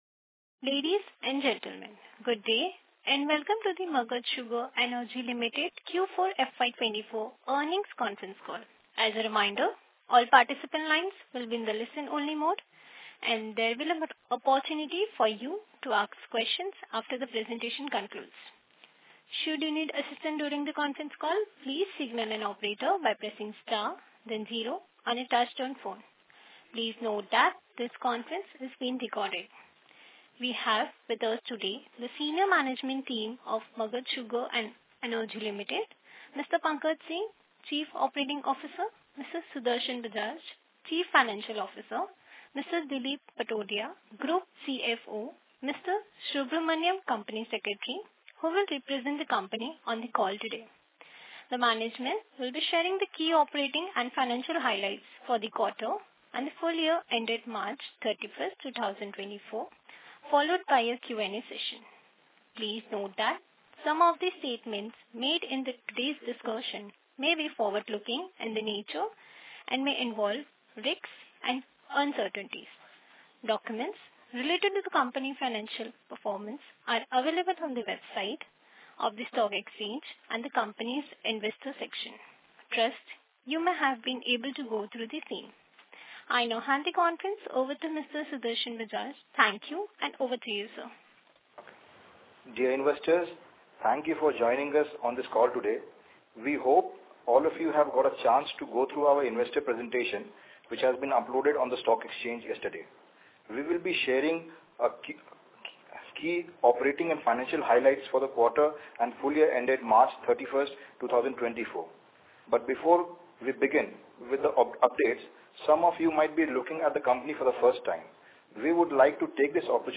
Earnings Call/Transcript